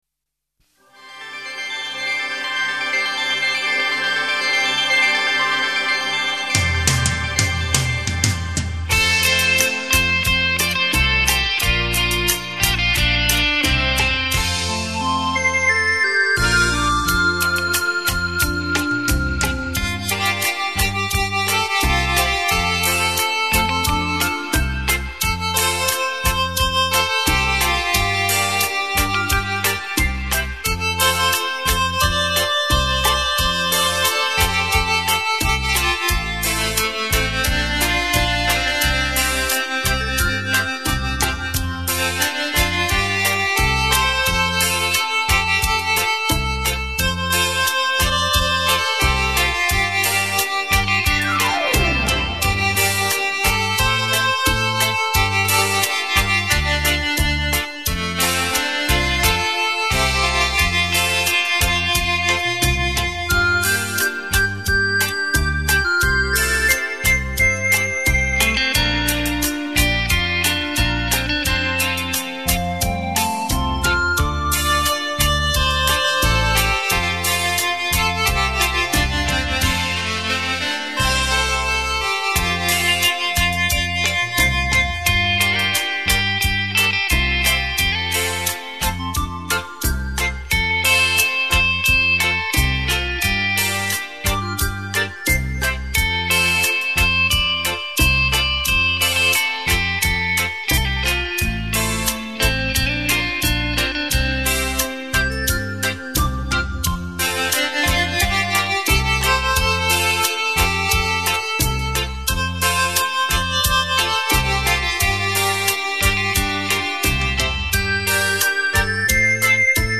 超時空繞場立體音效 發燒音樂重炫
百萬名琴魅力大出擊，旋律優美，曲曲沁心
電聲演繹發燒珍品·值得您精心收藏·細細聆賞...